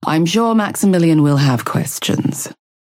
Calico voice line - I'm sure Maximilian will have questions.